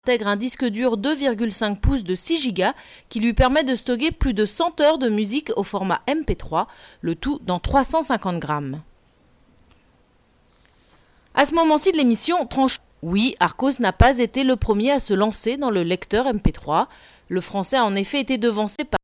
– Sautes de son lors des enregistrements !